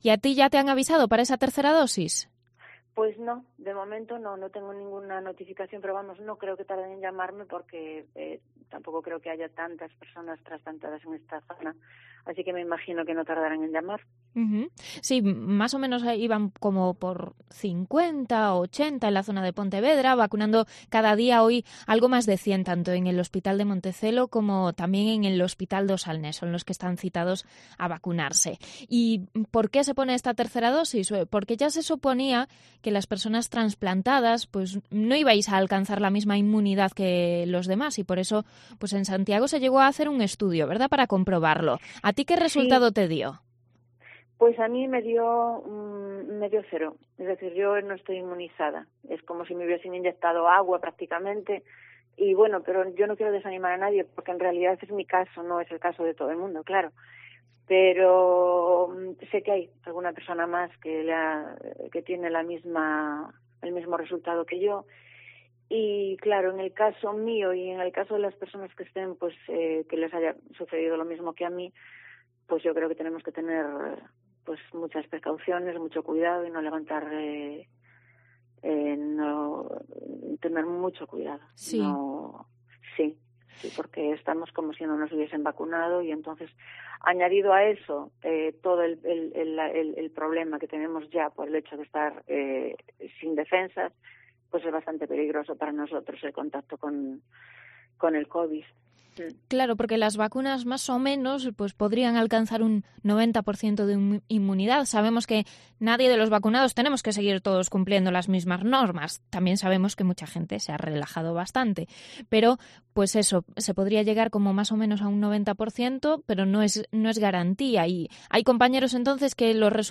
Testimonio de una transplantada de Vilagarcía pendiente de la tercera dosis de la vacuna contra la covid-19